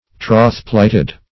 Trothplighted \Troth"plight`ed\, a. Having fidelity pledged.